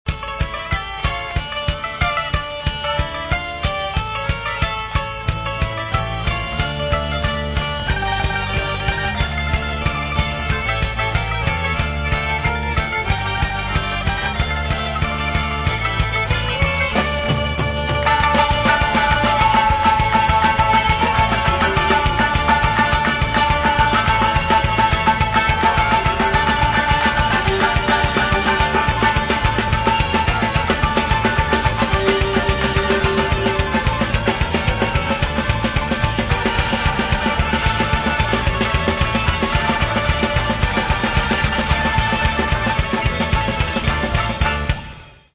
Catalogue -> Rock & Alternative -> Punk